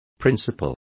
Complete with pronunciation of the translation of principles.